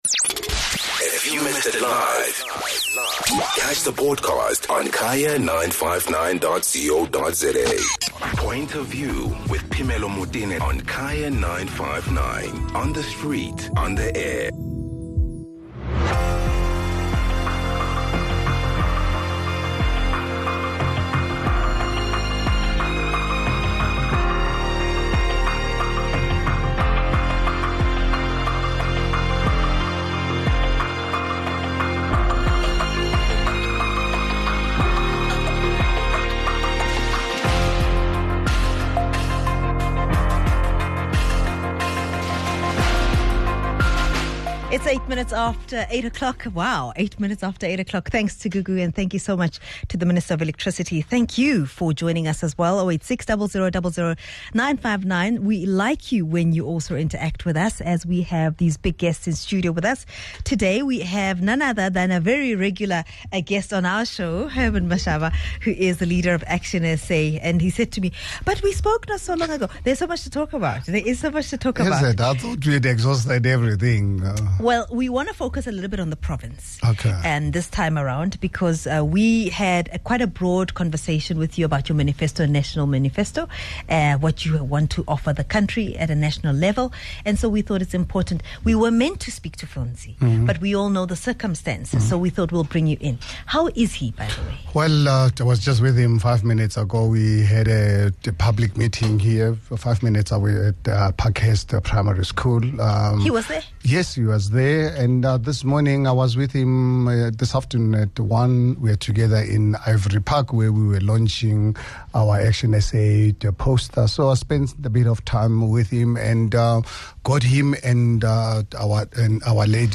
The party leader is in studio to unpack the Gauteng plans further.